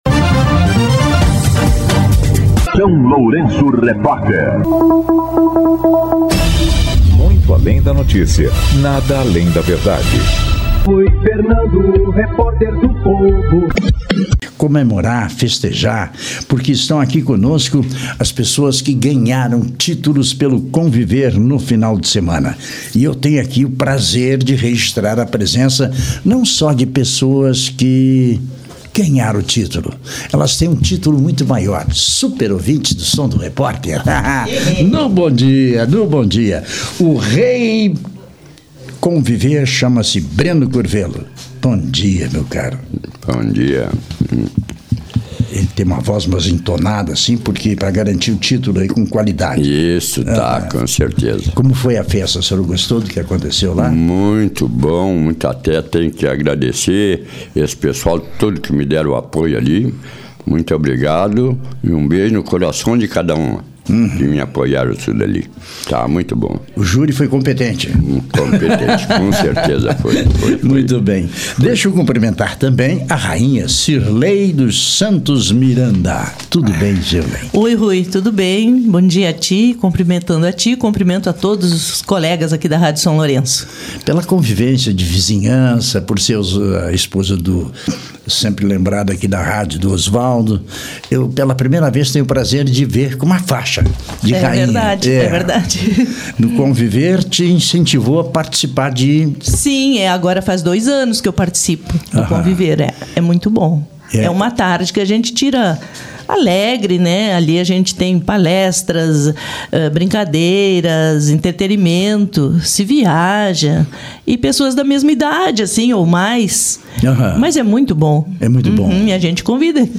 Hoje, a corte se fez presente no SLR RÁDIO.
Entrevista com a Corte do Conviver